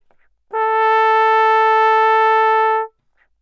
Obwohl die Tonhöhe bei allen Instrumenten bei 440 Hz liegt, sind noch klare klangliche Unterschiede wahrnehmbar
Horn_440Hz.wav